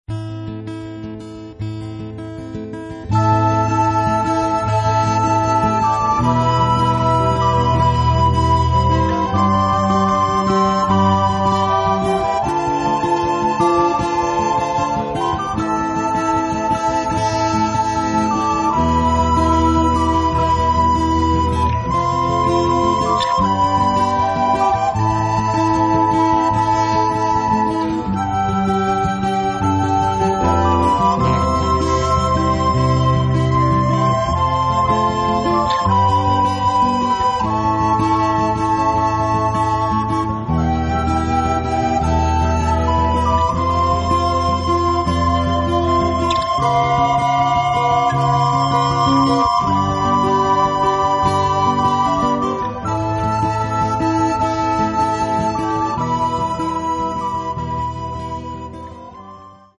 Рок
все инструменты, вокал